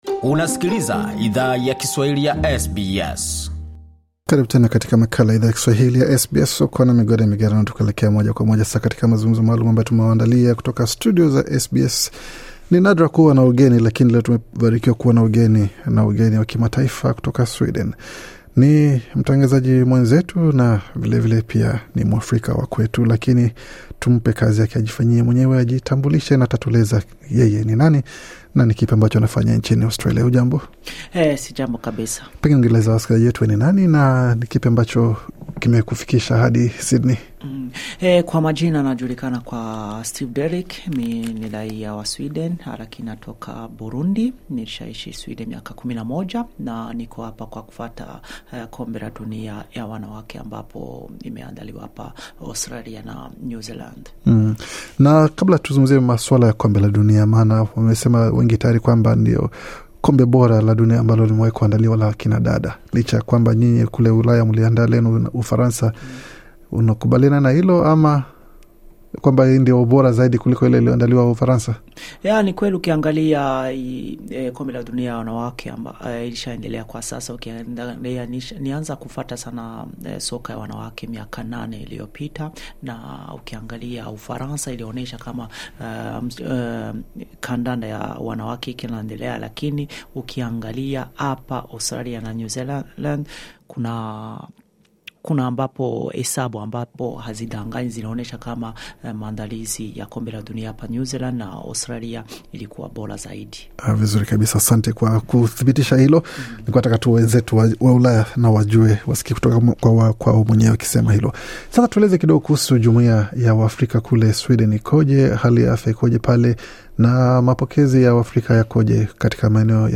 Alitembelea studio za SBS Swahili, ambako alitupa historia ya shirika lake la habari pamoja na changamoto yake kubwa yakupata wafanyakazi wanao stahiki nchini Sweden. Bonyeza hapo juu kwa mahojiano kamili.